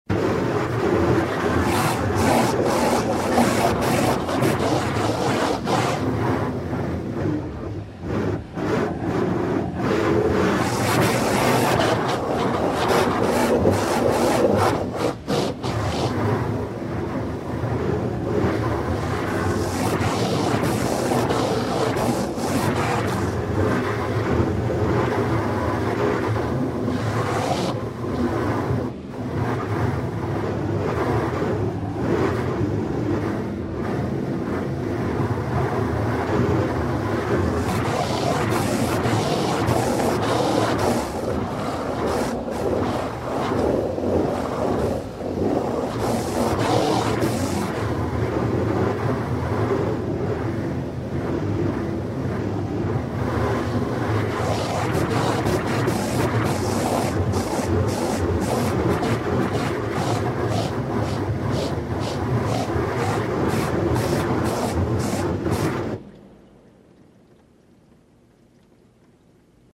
Машина под напором воды на ручной мойке